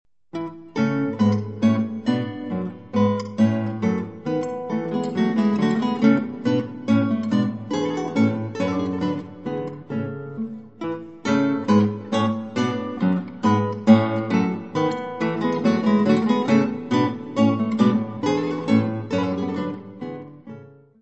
arranged by two guitars
guitarra
Music Category/Genre:  Classical Music
serenade in G major